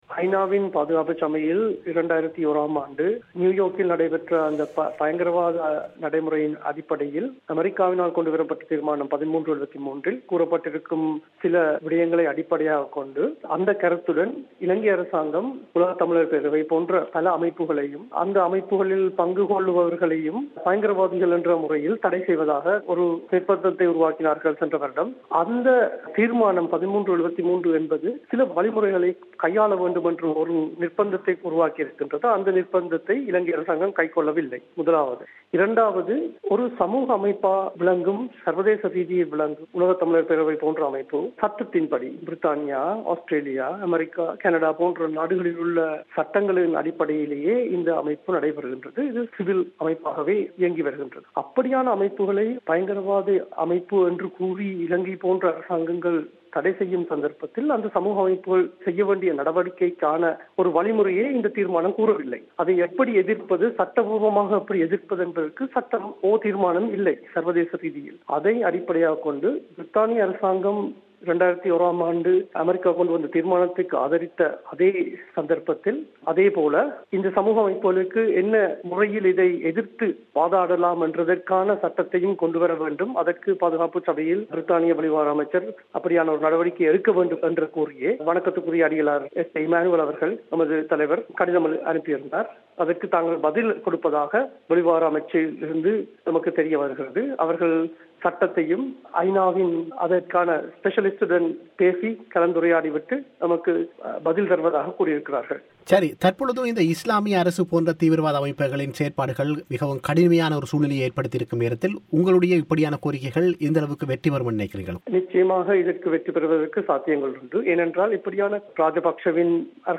தமிழோசைக்கு வழங்கிய செவ்வியை நேயர்கள் இங்கு கேட்கலாம்.